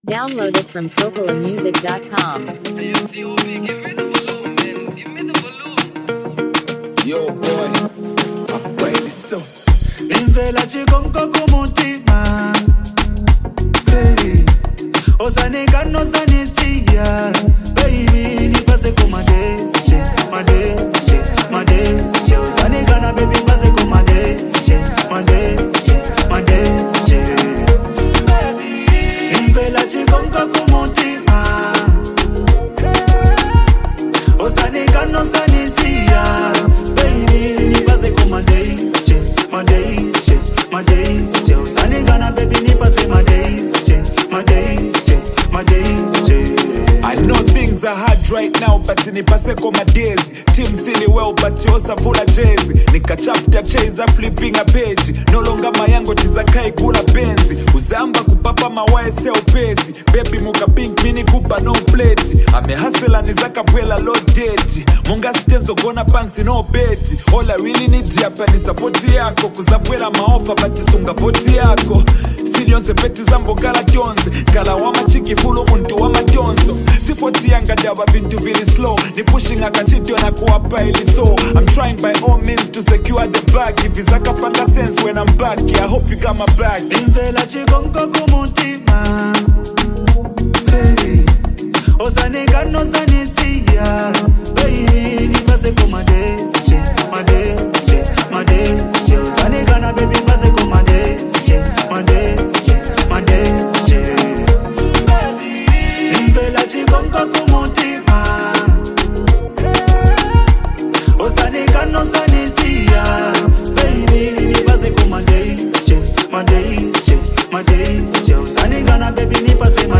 Zambian Music
heartfelt and reflective Zambian hip-hop track
smooth, soulful vocals on the hook